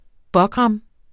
Udtale [ ˈbʌgʁɑm ]